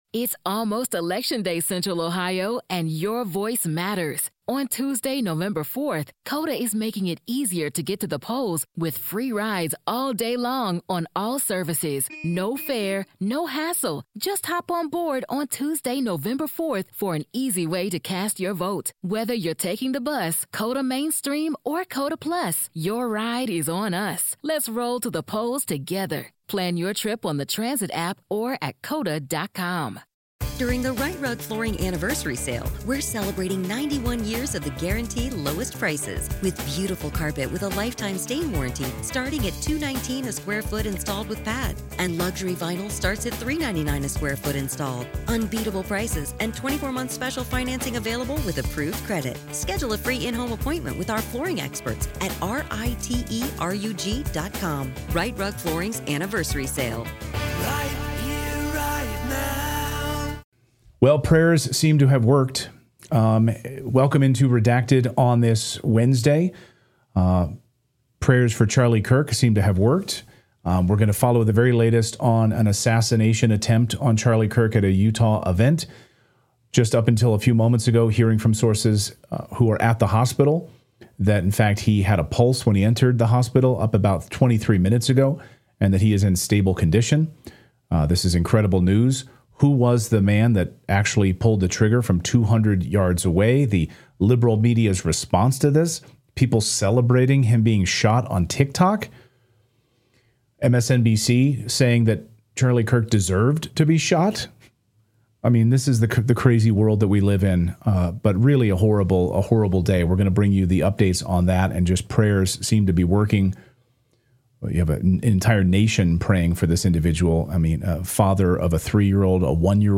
We started off today's show, hoping and praying that conservative commentator Charlie Kirk, who had been shot would survive his wounds, but as the show progressed, we learned live that the assassin had been successful. What you're listening to is a live breaking news broadcast as these events unfolded we will have many more details and analysis during tomorrow's broadcast.